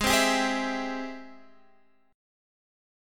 G#+M7 chord